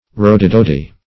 Rowdydowdy \Row"dy*dow`dy\